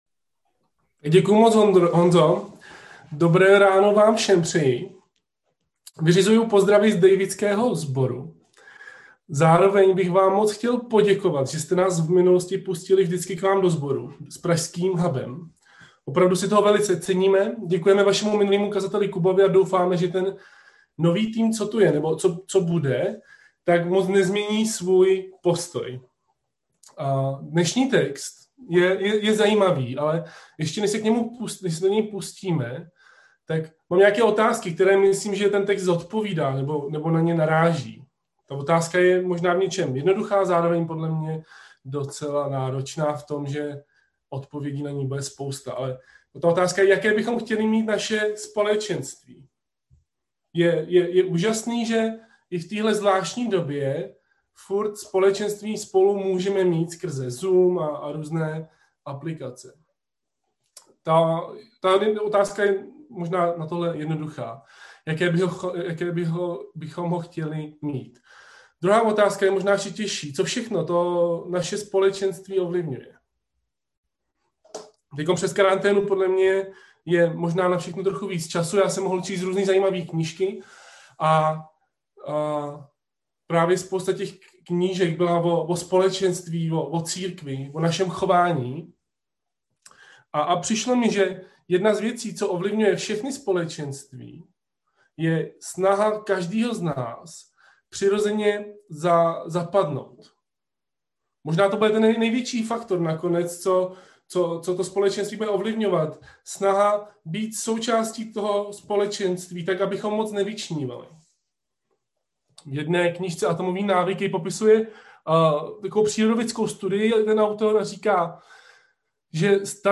Nedělní kázání 21.2.2021